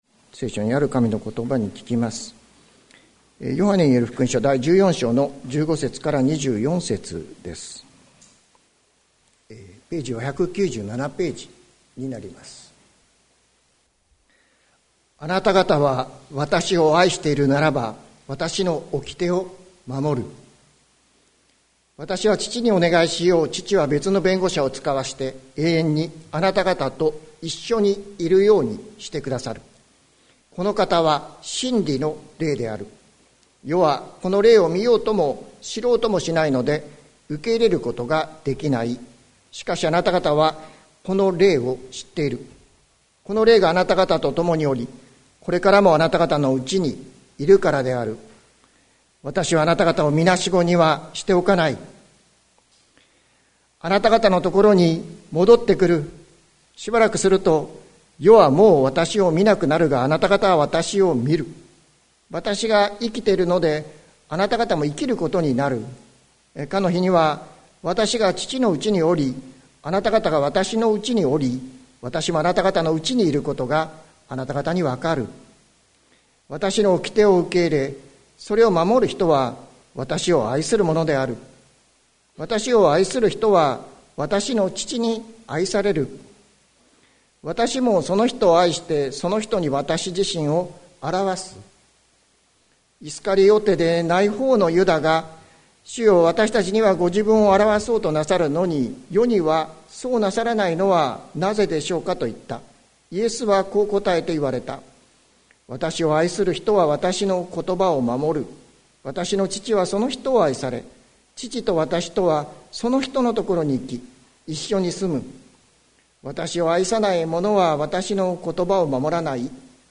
2023年06月25日朝の礼拝「ひとりぼっちじゃない」関キリスト教会
説教アーカイブ。